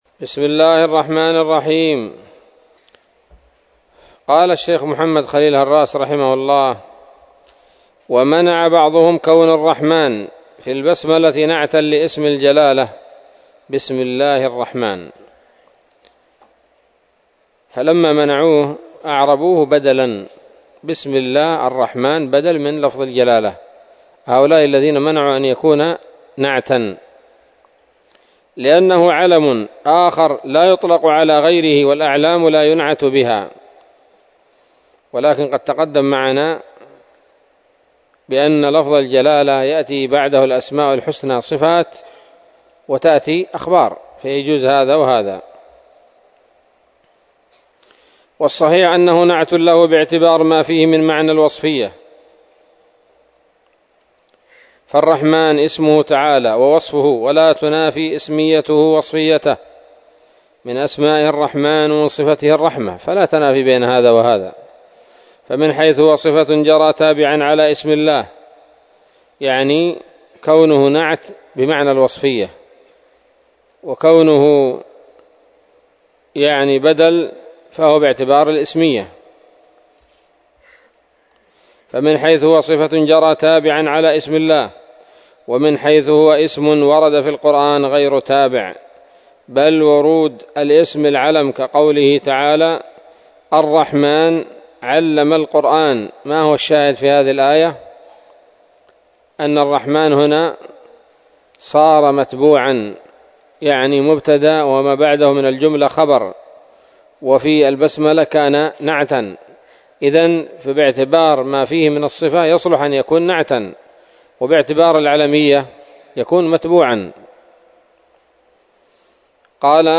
الدرس السابع من شرح العقيدة الواسطية للهراس